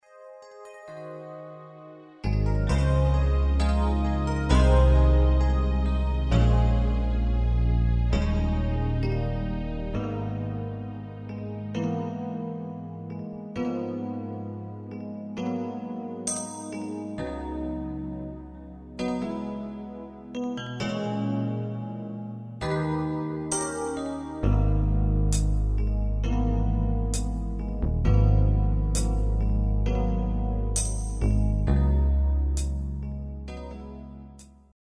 Demo/Koop midifile
Genre: Nederlands amusement / volks